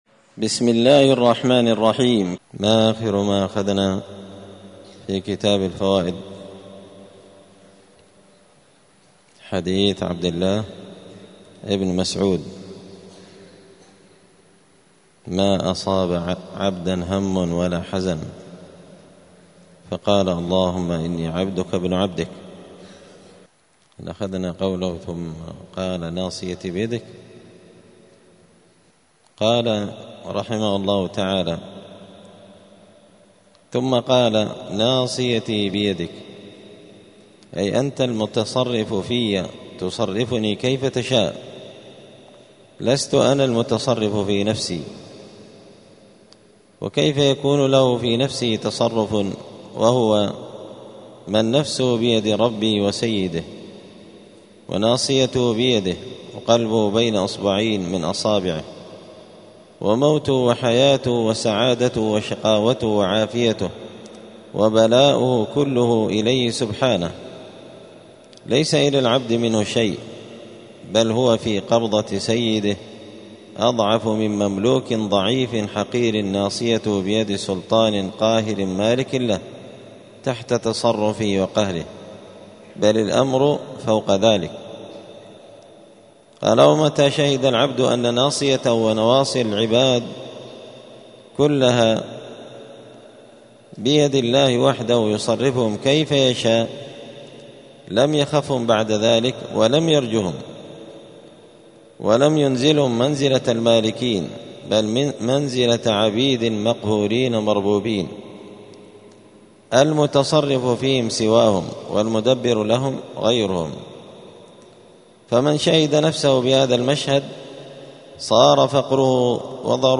الجمعة 22 ربيع الثاني 1446 هــــ | الدروس، دروس الآداب، كتاب الفوائد للإمام ابن القيم رحمه الله | شارك بتعليقك | 15 المشاهدات